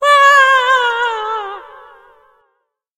• Качество: высокое
Падение мамы звук с высоты